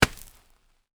Heavy (Running)  Dirt footsteps 4.wav